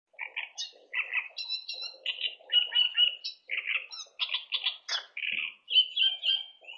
摘要鸟类
描述：用噪音限制器处理的鸟（芦苇莺？）的歌声的录音，给人一种抽象的气泡合成器般的声音
Tag: 过滤 歌曲